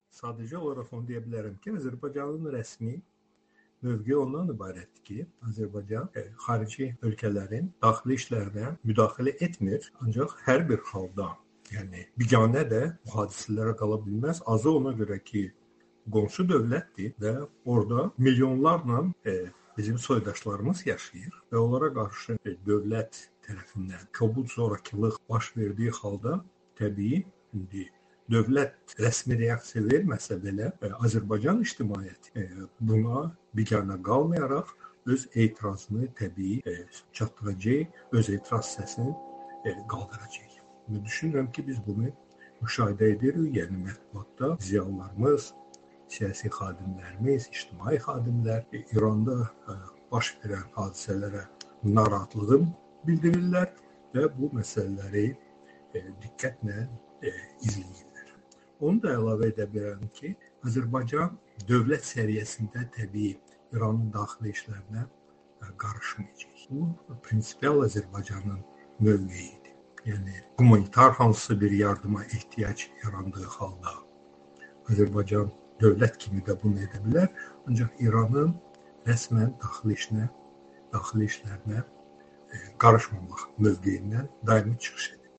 Bu sözləri Amerikanın Səsinə müsahibəsində Azərbaycan millət vəkili Rasim Musabəyov İrandakı etiraz hərəkatının Azərbaycana təsirlərini şərh edərkən deyib.